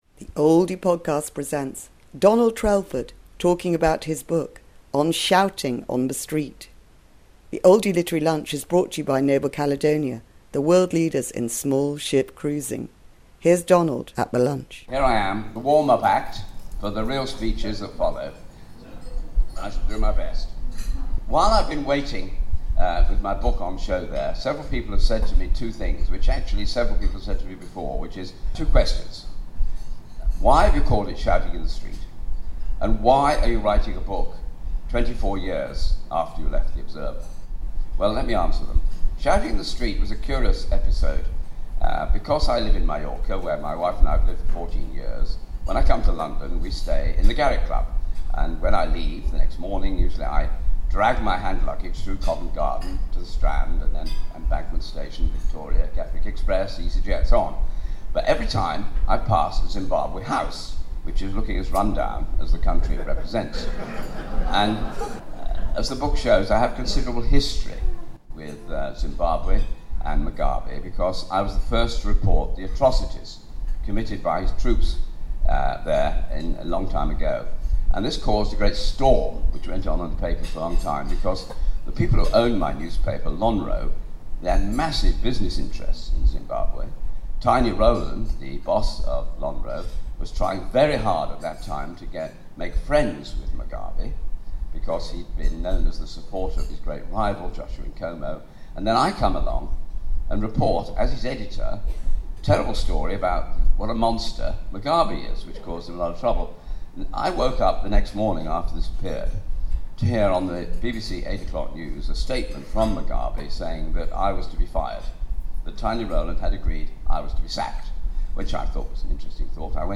Ex editor of The Observer, Donald Trelford talks about his book Shouting in the Street - Adventures and Misadventures of a Fleet Street Survivor at The Oldie Literary lunch.